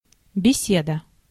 Beseda (Russian: Беседа, IPA: [bʲɪˈsʲedə]